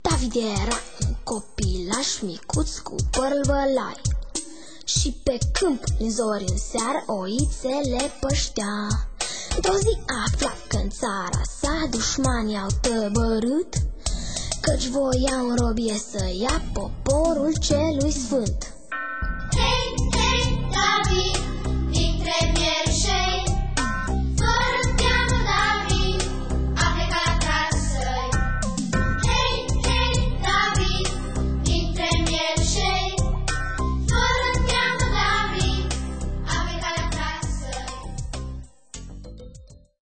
linia melodica si aranjamentul orchestral
a copiilor si din vocile lor curate, cristaline